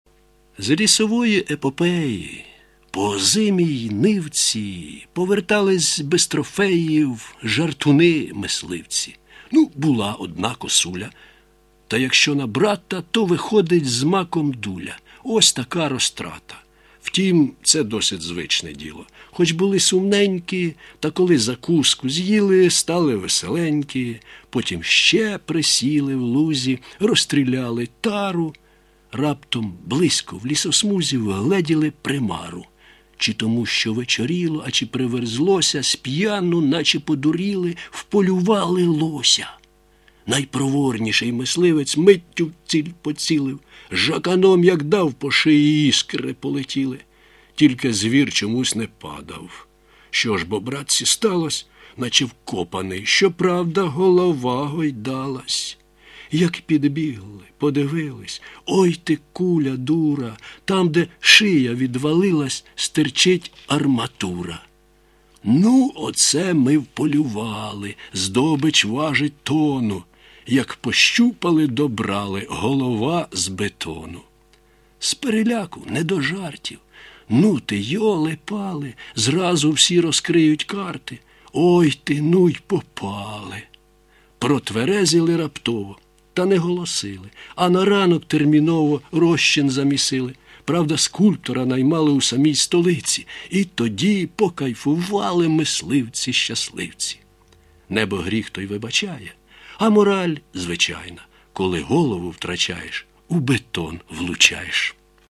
Як же суперськи Ви читаєте! friends 16